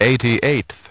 Транскрипция и произношение слова "eighty-eighth" в британском и американском вариантах.